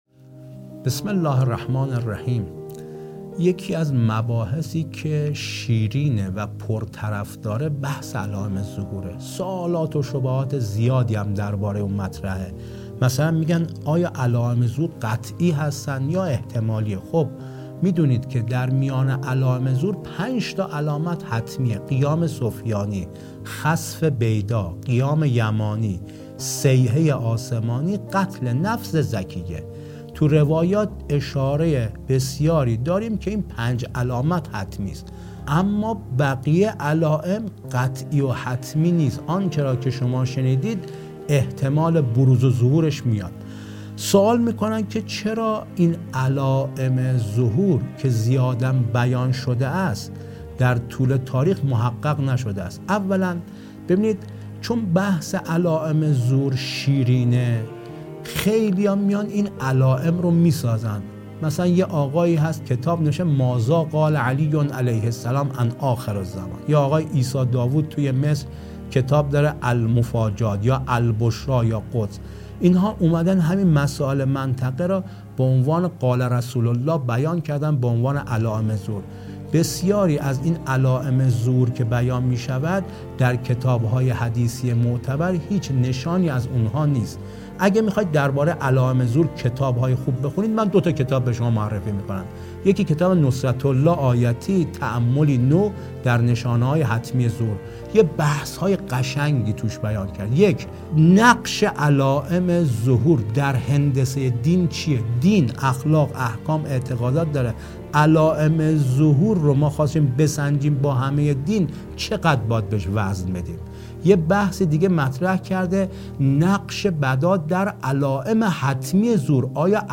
در گفتگویی تخصصی با رسانه حوزه